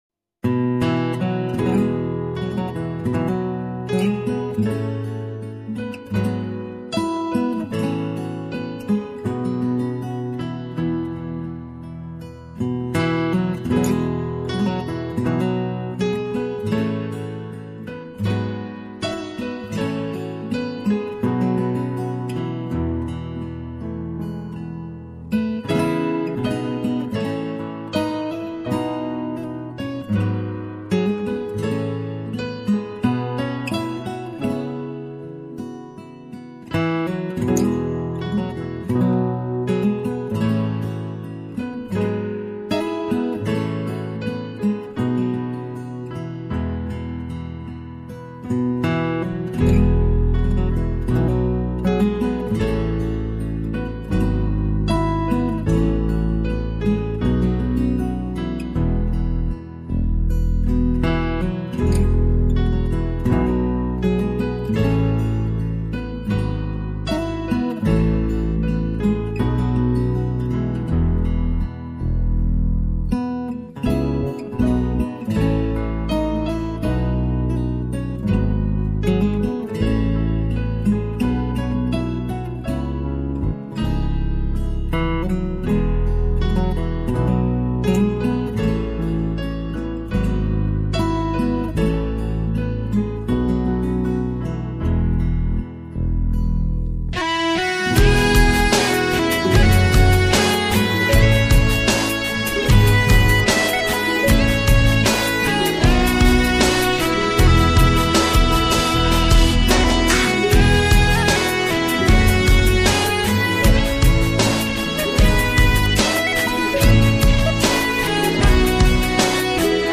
苏格兰风笛如泣苍凉的旋律，让我们窥见苏格兰音乐的灵魂。